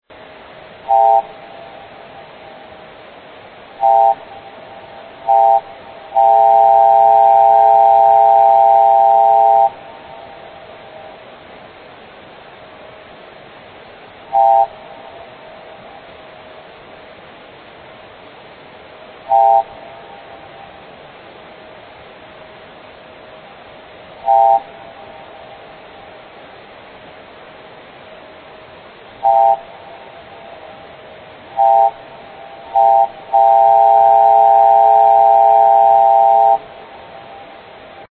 • 18khz Noise
Where Found: 18 kHz (yes, that's kilohertz!) in the VLF band.
Notes: It sure sounds like some kind of data transmission.
18khz.mp3